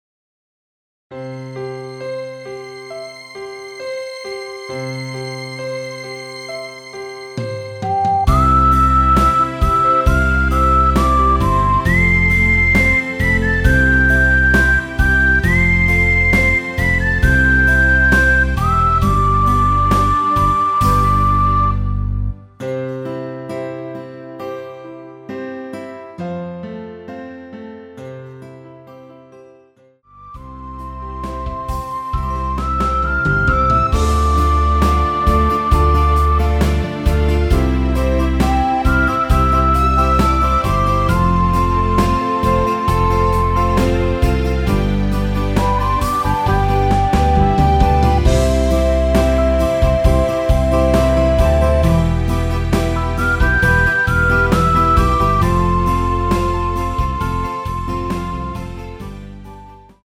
대부분의 여성분이 부르실수 있는키로 제작 하였습니다.
앞부분30초, 뒷부분30초씩 편집해서 올려 드리고 있습니다.
중간에 음이 끈어지고 다시 나오는 이유는